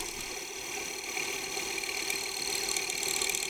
Reel 10.ogg